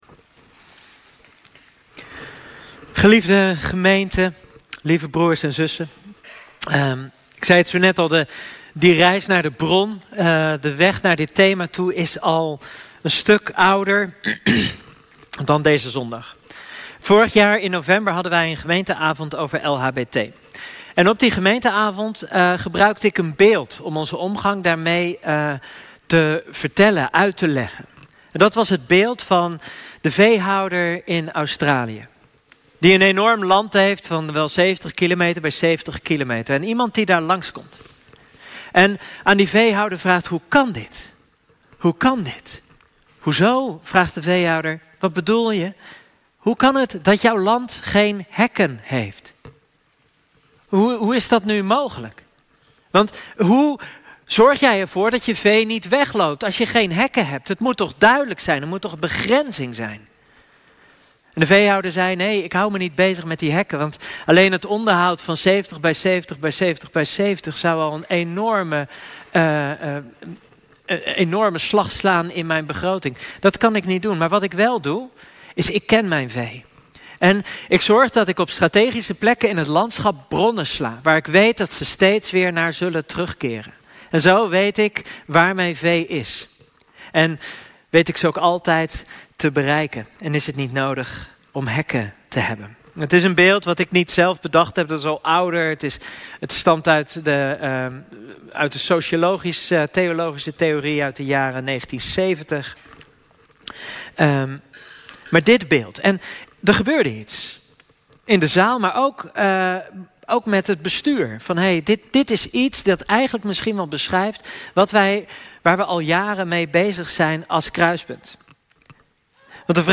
Kerkdienst - 7 september 2025